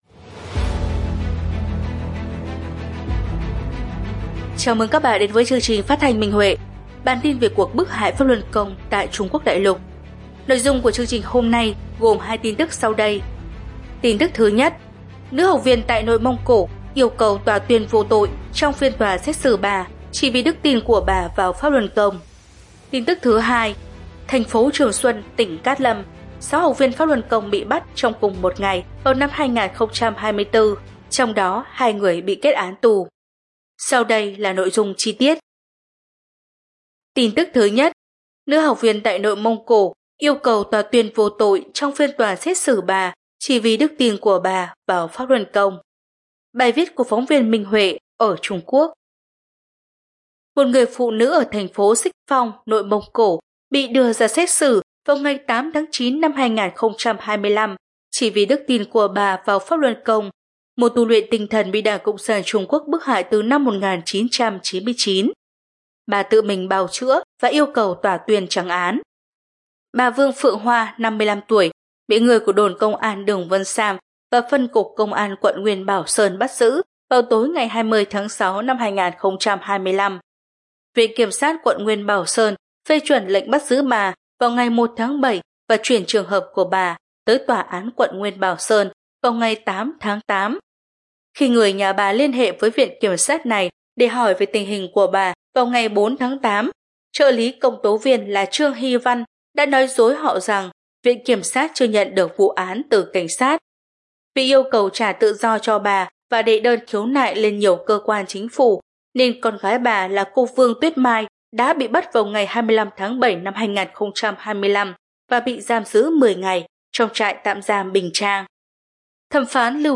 Chương trình phát thanh số 243: Tin tức Pháp Luân Đại Pháp tại Đại Lục – Ngày 18/9/2025